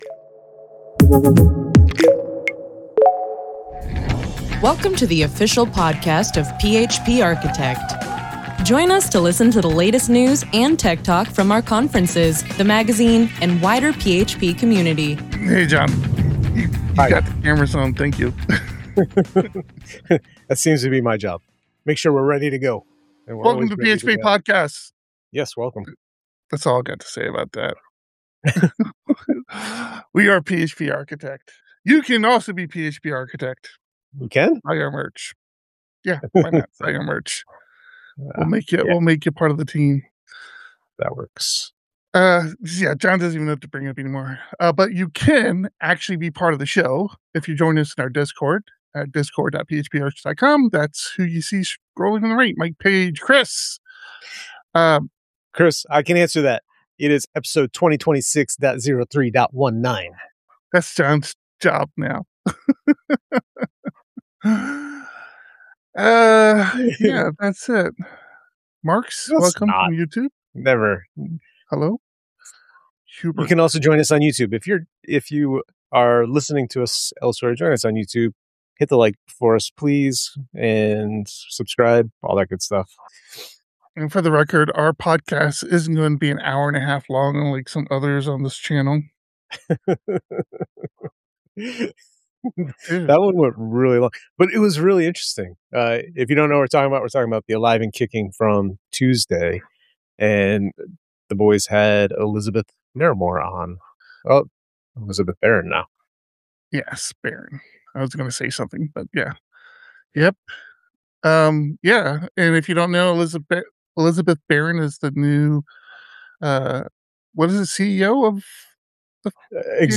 The PHP Podcast streams live, typically every Thursday at 3 PM PT.